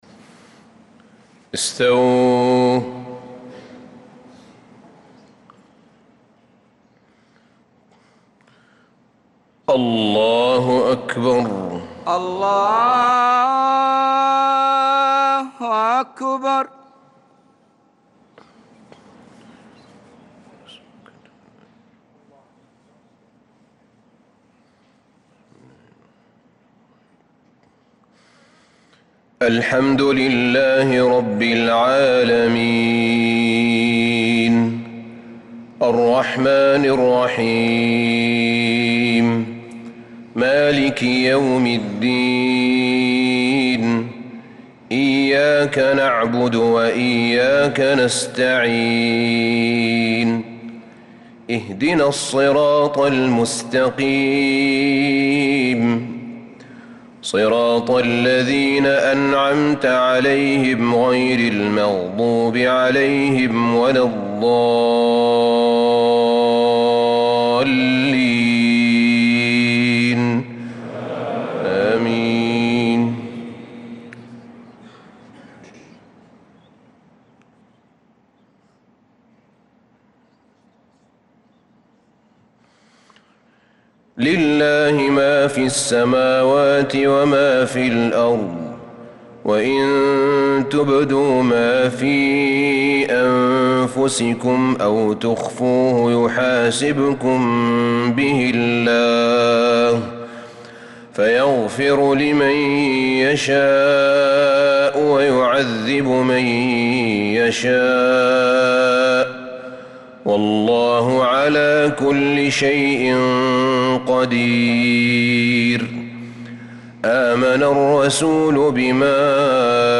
صلاة المغرب للقارئ أحمد بن طالب حميد 21 رجب 1446 هـ